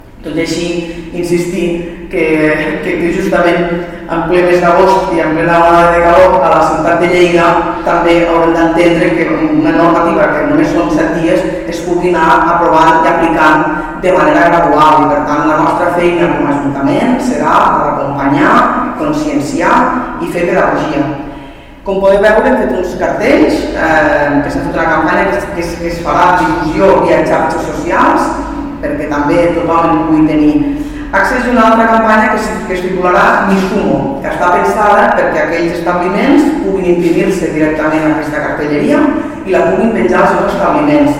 Tall de veu de l'alcalde accidental, Toni Postius, sobre l'aplicació per part de la Paeria de noves mesures per adaptar-se al decret de l’Estat d’estalvi energètic (544.3 KB) Tall de veu de la tinent d'alcalde Jordina Freixanet sobre les campanyes impulsadesp er la Paeria sobre l'aplicació de mesures del decret de l’Estat d’estalvi energètic (415.1 KB)